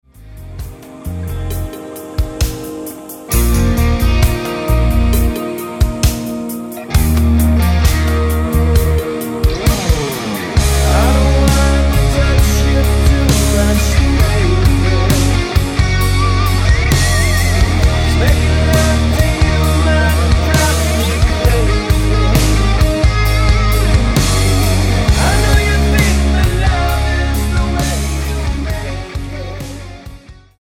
Tonart:Eb mit Chor